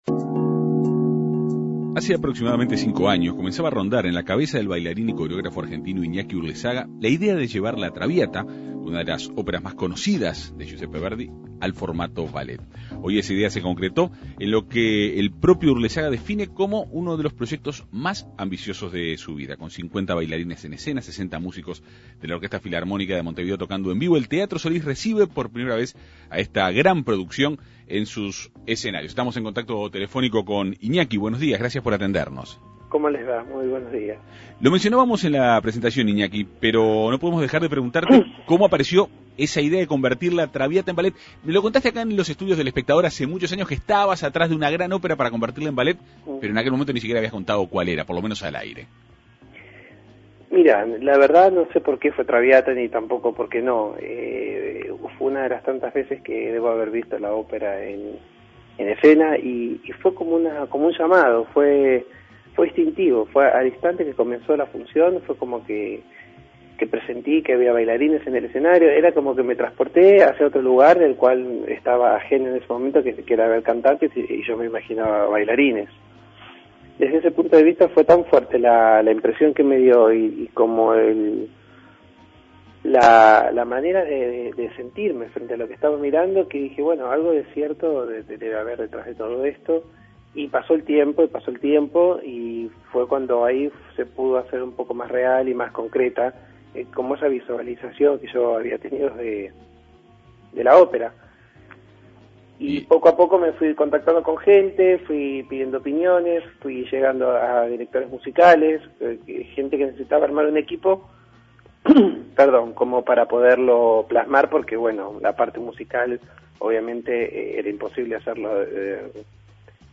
El Teatro Solís recibe a la gran producción del argentino, quien dialogó en la Segunda Mañana de En Perspectiva.